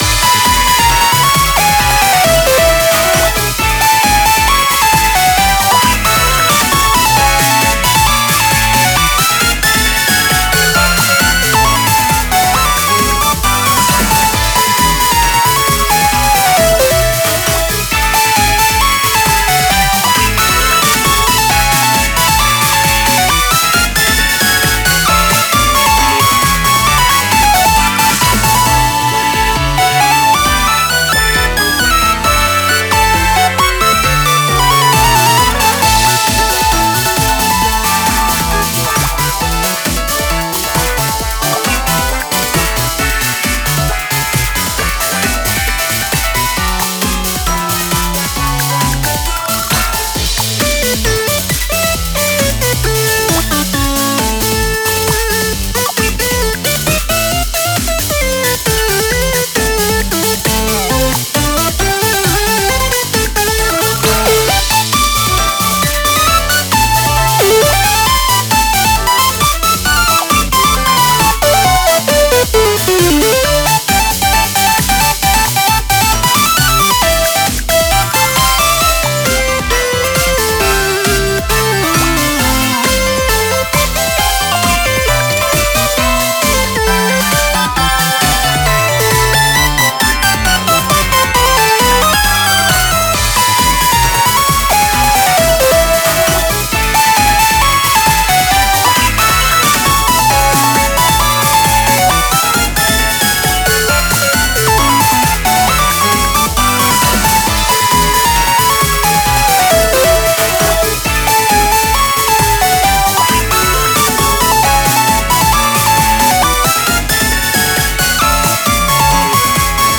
可愛い雰囲気の曲です。
元気でコロコロしてます
タグ かわいい